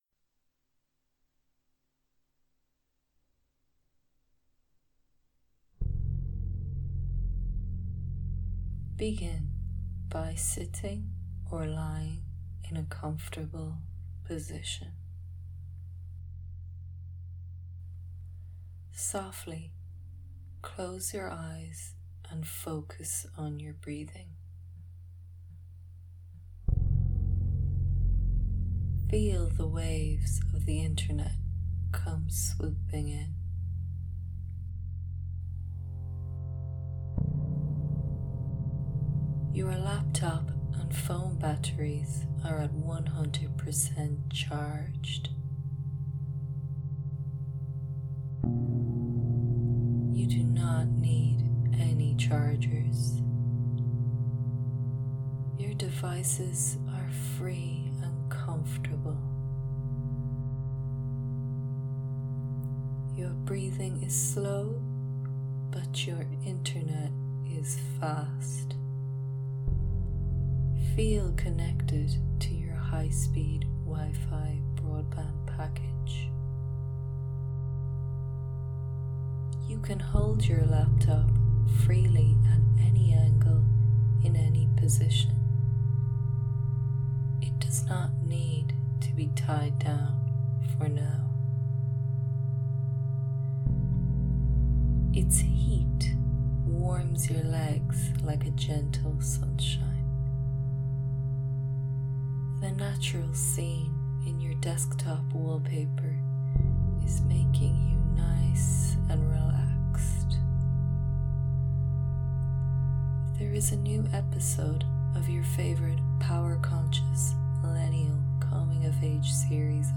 misguided-meditation-3.mp3